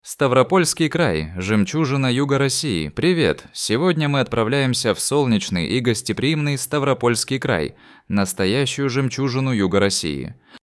Озвучка большого текста онлайн